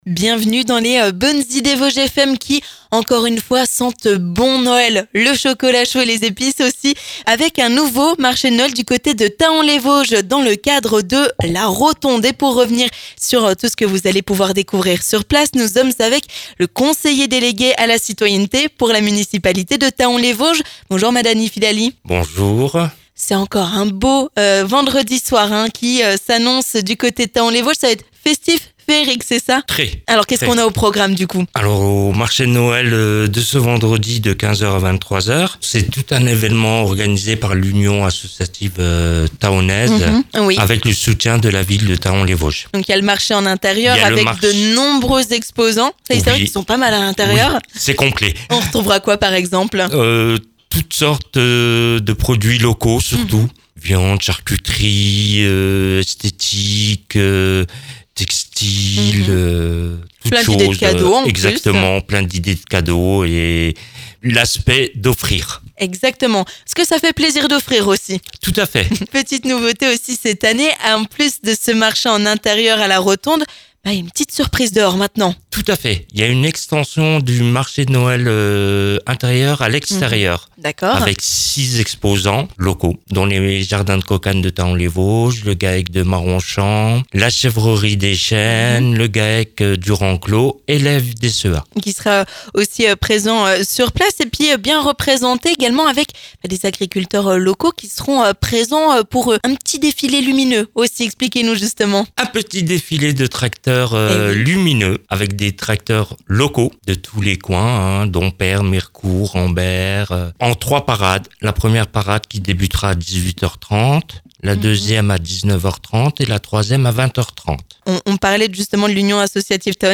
Dans Les Bonnes Idées Vosges FM, nous avons rencontré Madhani Filali, conseiller municipal pour la ville de Thaon-les-Vosges, pour en savoir plus sur ce marché de Noël.